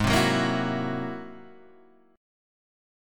G#+9 chord {4 3 4 3 5 2} chord